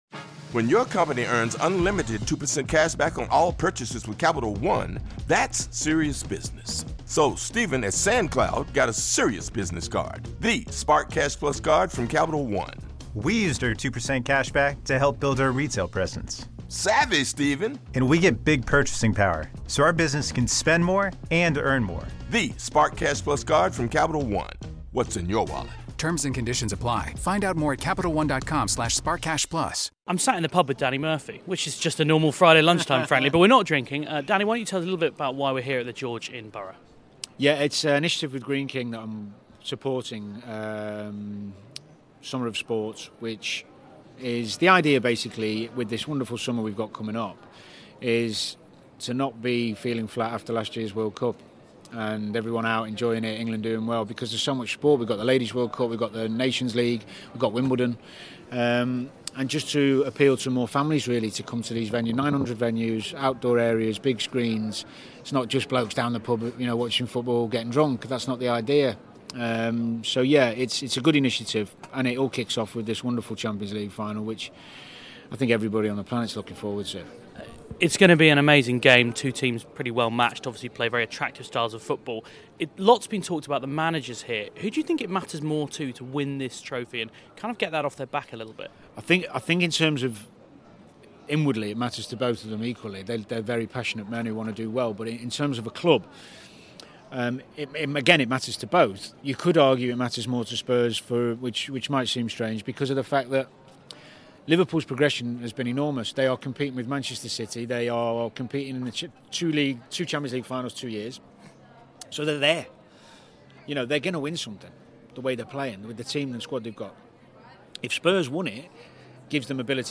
caught up with him in a friendly beer garden to chat all things Champions League final.